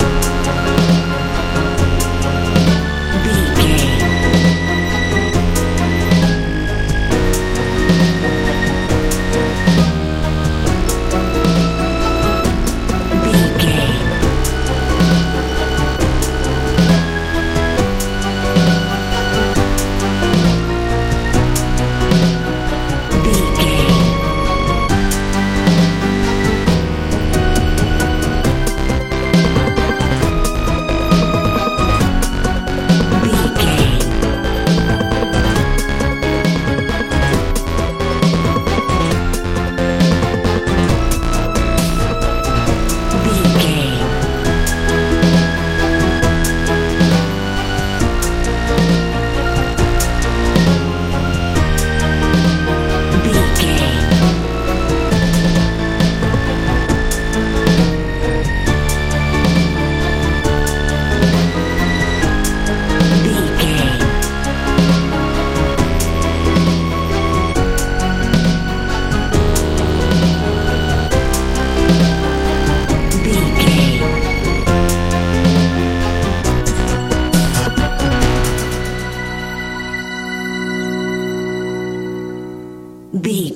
modern dance
Ionian/Major
C♯
magical
mystical
piano
synthesiser
bass guitar
drums
80s
suspense
tension
strange